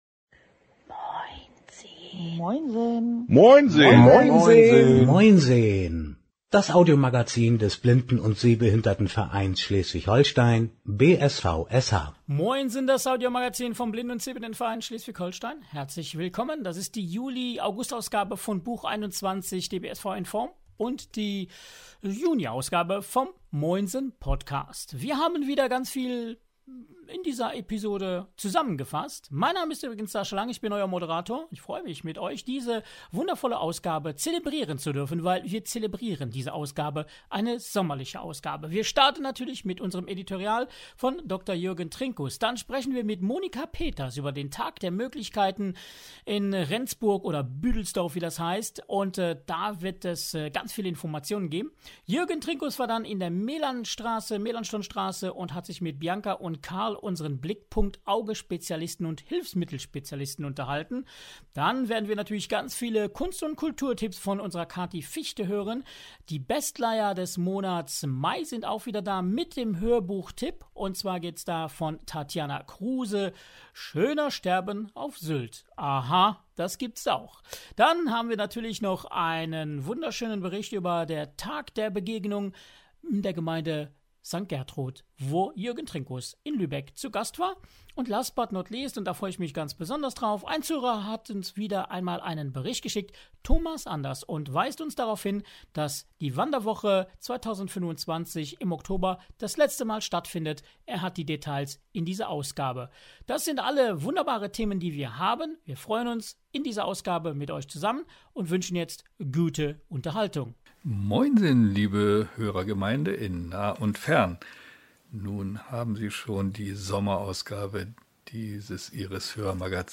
„MoinSeHn“ ist das Hörmagazin des Blinden- und Sehbehindertenvereins Schleswig-Holstein e. V. (BSVSH), ist unsere Einladung zum zuhören und mitmachen!Moderation und Produktion